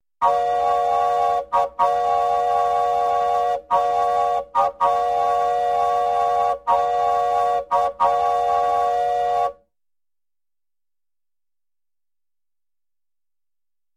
Звук гудка паровоза и гудка тепловоза